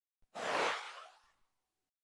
Звук відправленого електронного листа